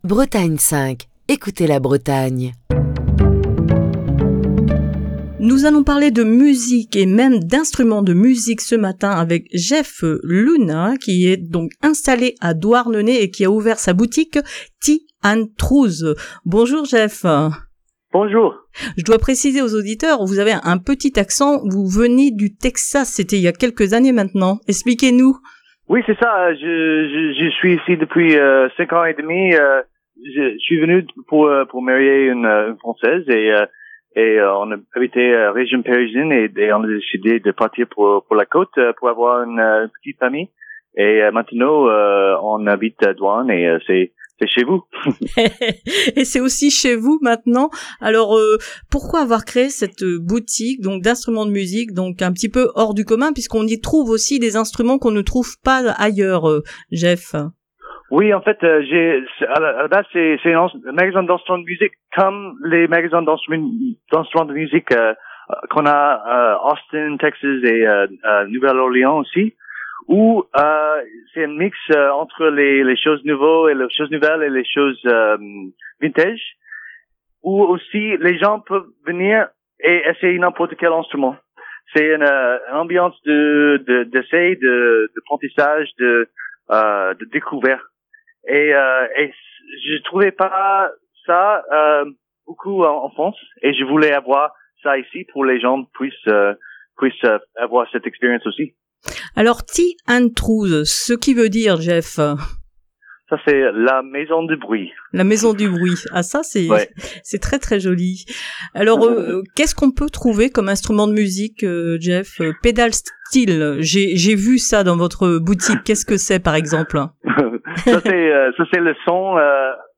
Ce matin, dans le coup de fil